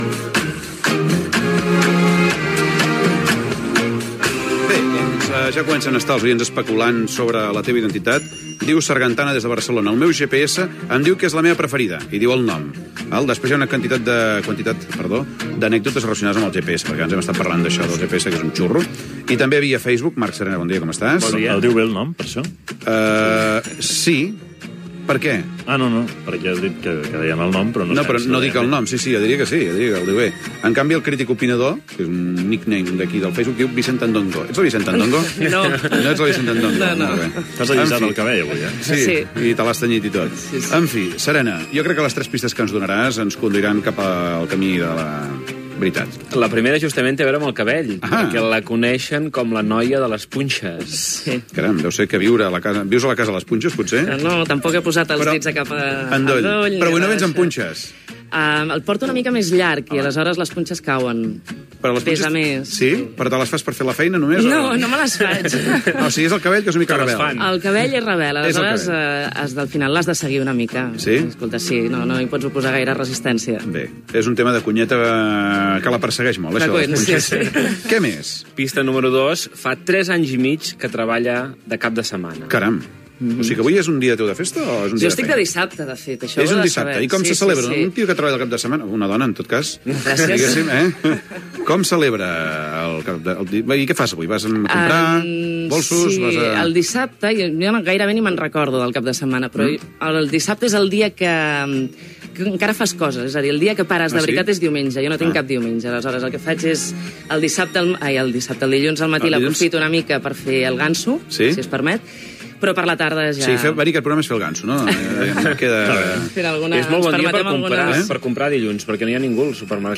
Entrevista a la periodista Agnès Marquès